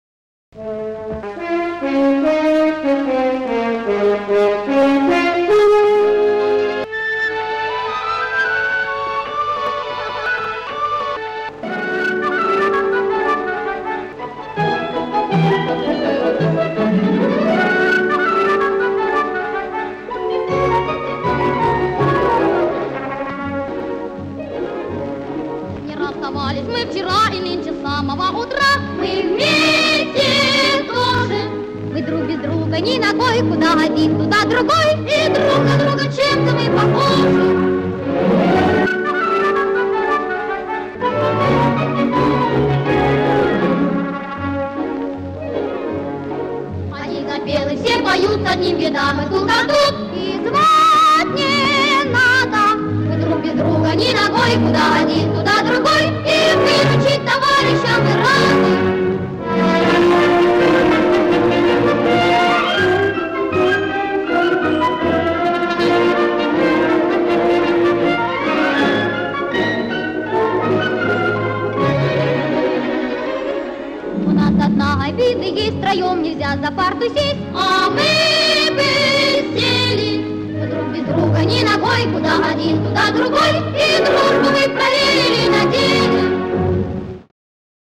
По возможности устранены наложенные шумы и разговоры.